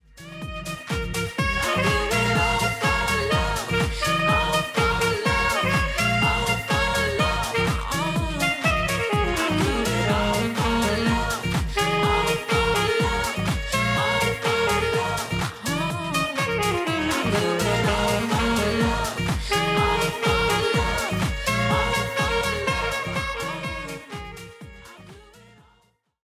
A captivating, charismatic and highly versatile saxophonist.
Captivating, charismatic and highly versatile saxophonist.